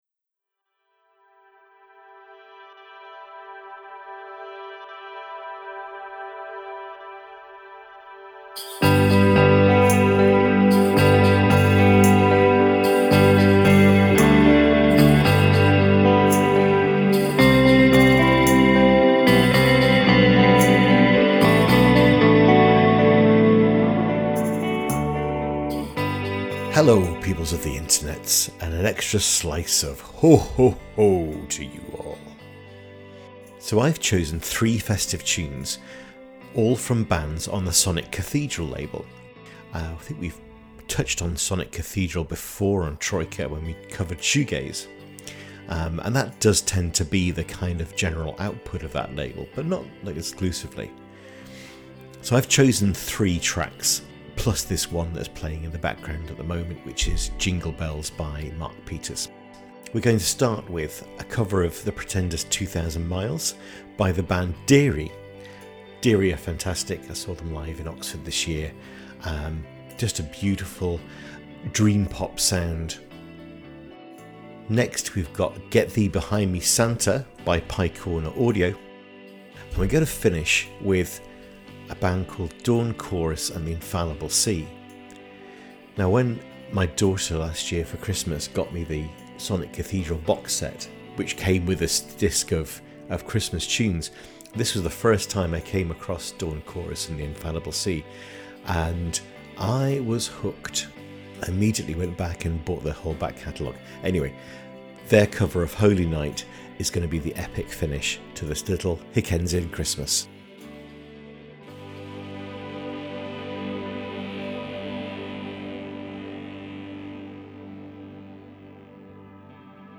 Three pieces of thematically linked music